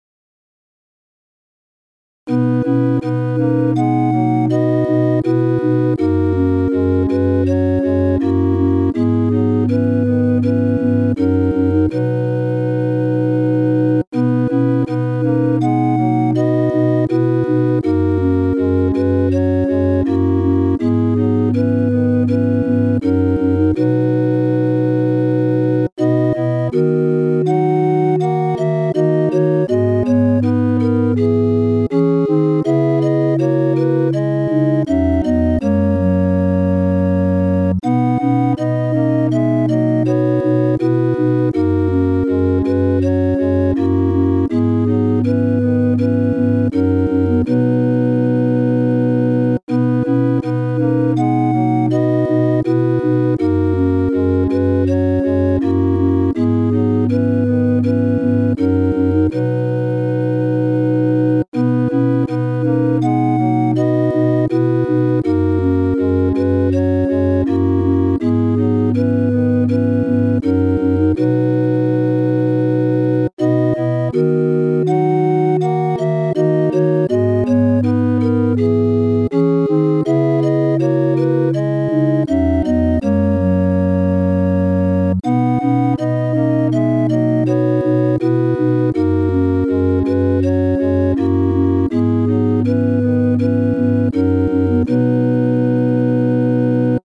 Quodlibet ; weltlich Charakter des Stückes: humorvoll
TTBB + solo (4 Männerchor Stimmen ) Solisten: Soprano (1)
Tonart(en): H-Dur